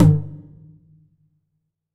9HITOM1.wav